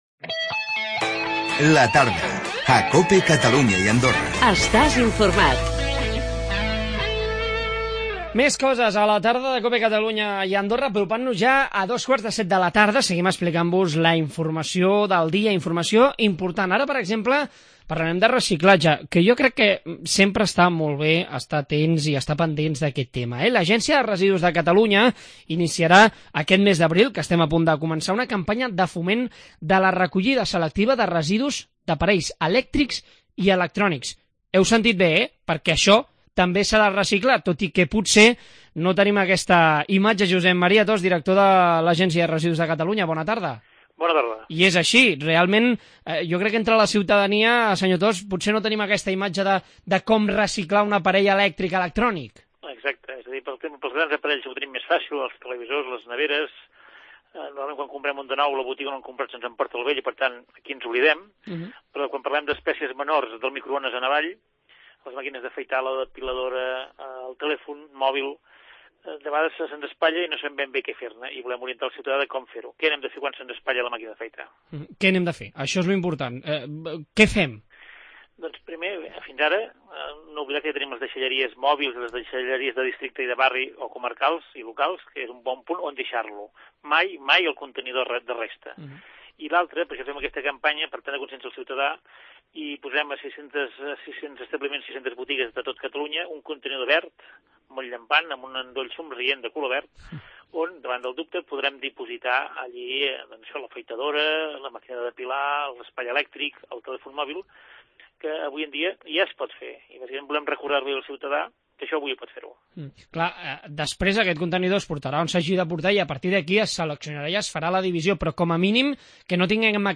L'Agència de residus inicia una campanya de recollida selectiva d'aparells electrònics. Hem parlat amb Josep Maria Tost, director de Agència de Residus de Catalunya?